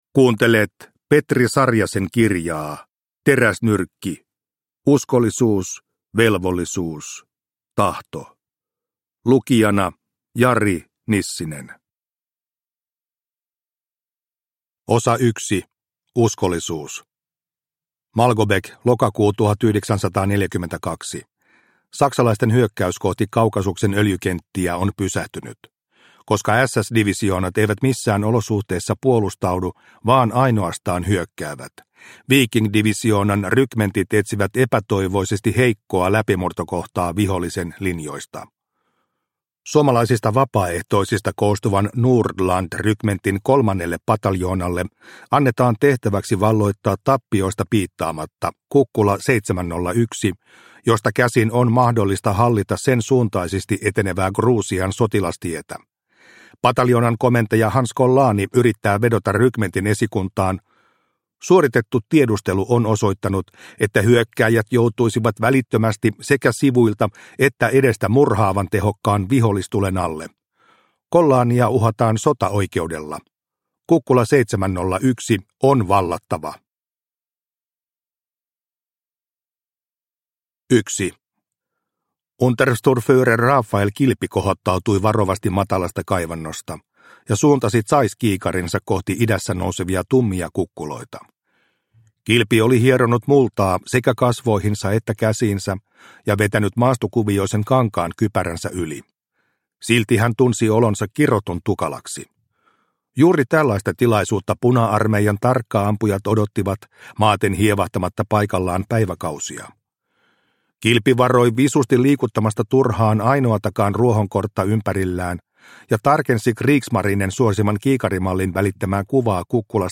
Teräsnyrkki – Ljudbok – Laddas ner